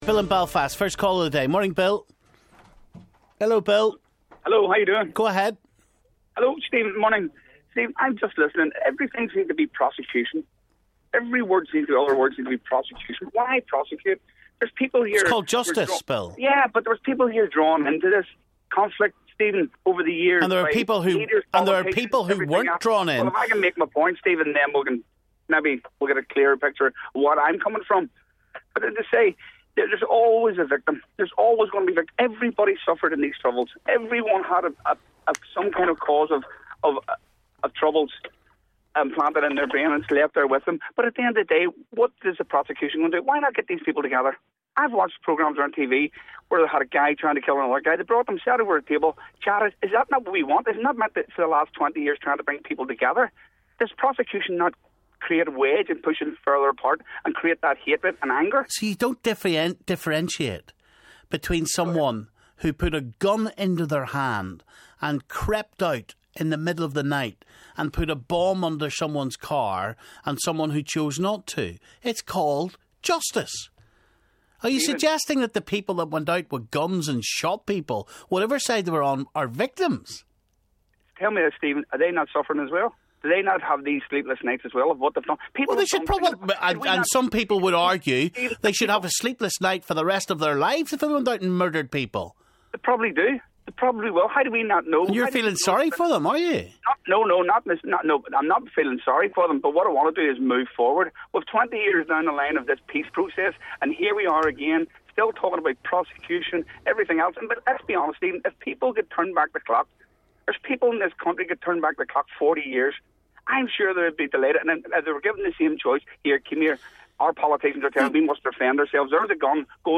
we took your calls